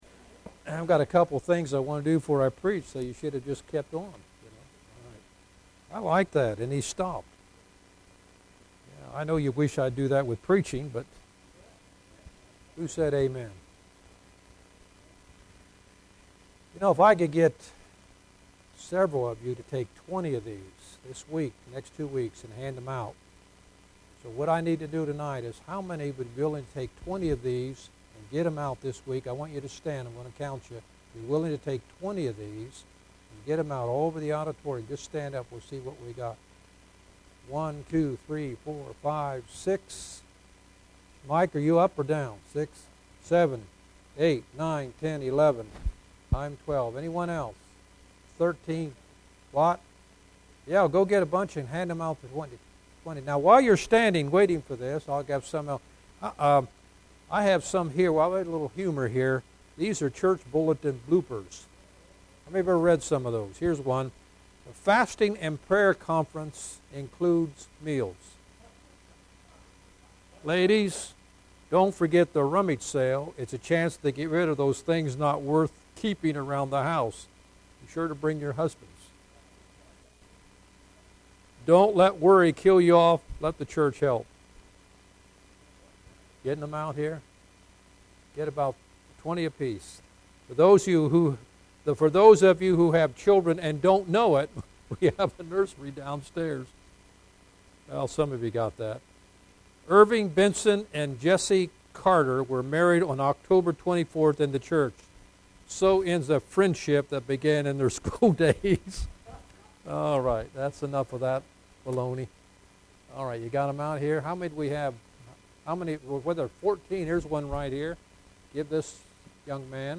Passage: Revelation Service Type: Sunday PM